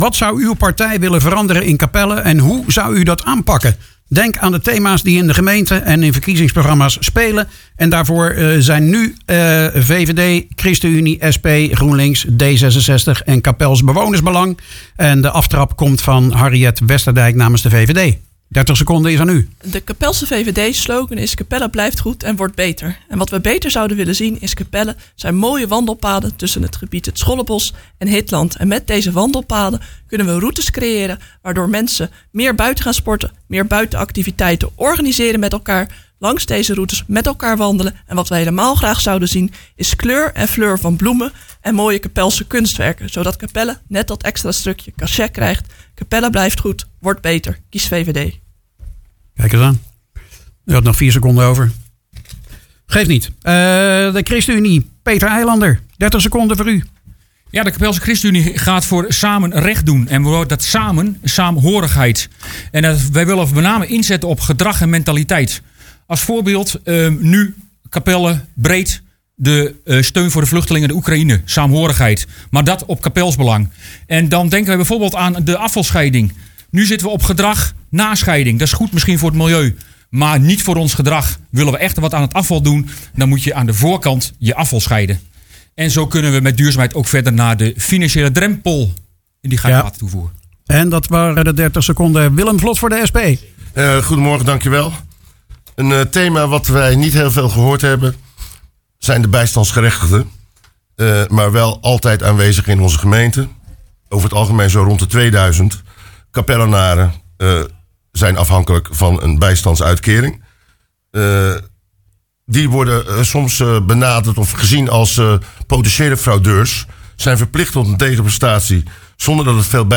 Op zaterdag 12 maart was in de studio van Radio Capelle het laatste verkiezingsdebat voordat de stembureaus opengaan.
Je hoort�VVD, ChristenUnie, SP, GroenLinks, D66 en Capels Bewoners Belang. De antwoorden hadden betrekking op onder andere�energietarieven, isoleren van huizen, omgaan met energie en de juiste informatie over duurzaamheid voor bewoners.